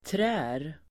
Uttal: [trä:(de)r]